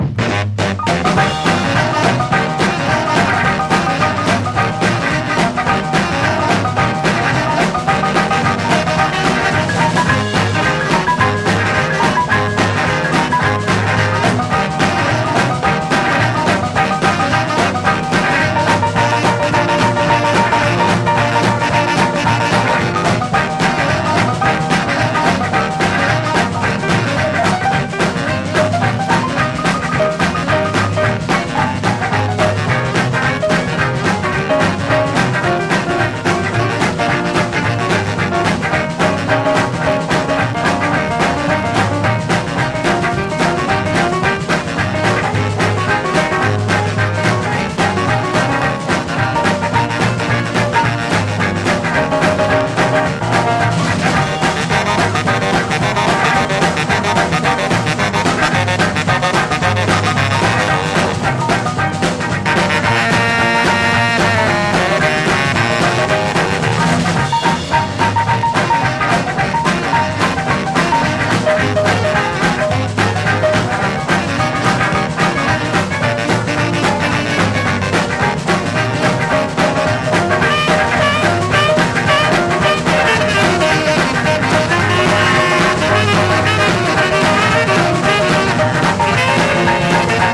ブリブリのサックスを交えてテンション高く進んでいく